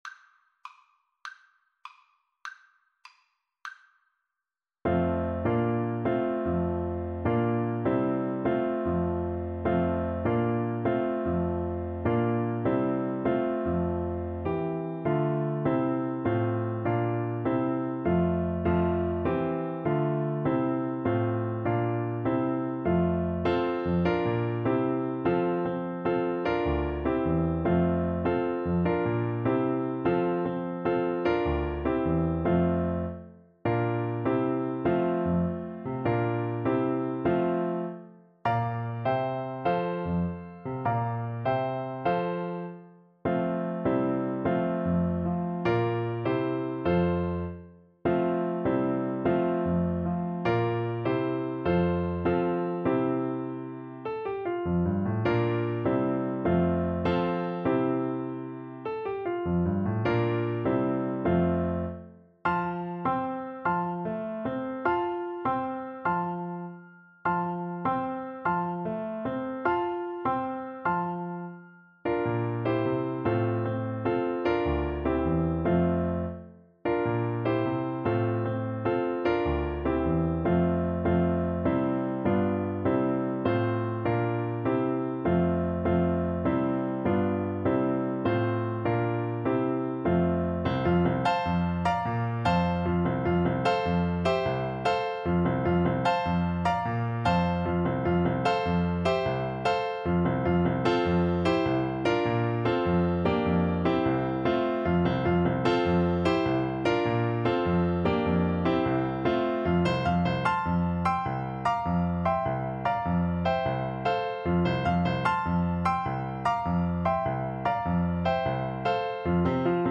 6/8 (View more 6/8 Music)
Classical (View more Classical Flute Music)